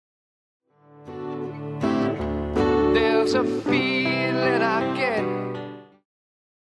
Genere: hard rock